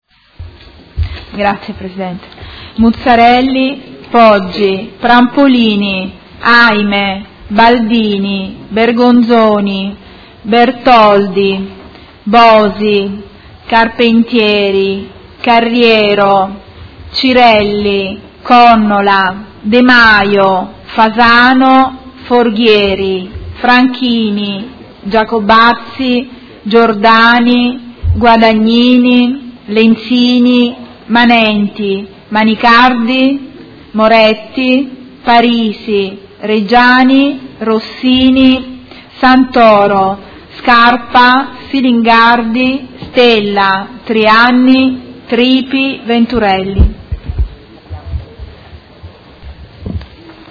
Appello